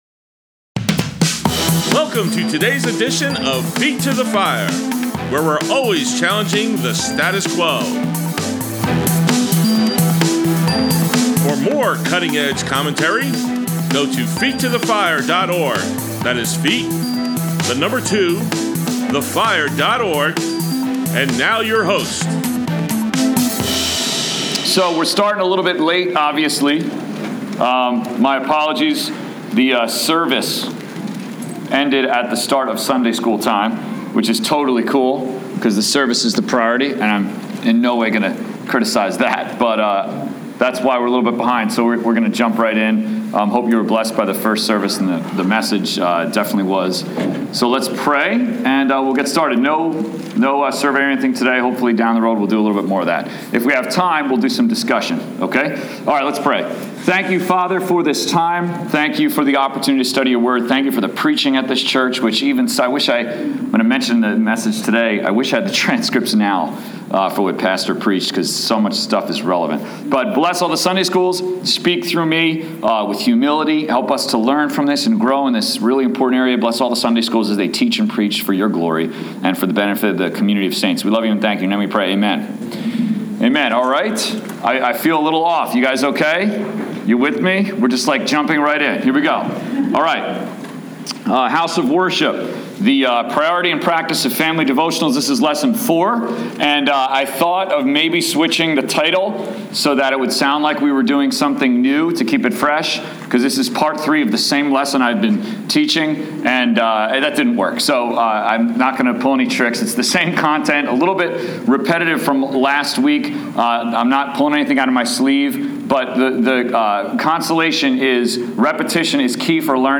Grace Bible Church, Adult Sunday School, 2/7/16